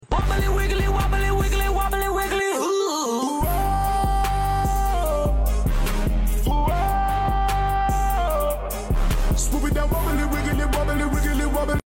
WOBBLY WIGGLY sound effects free download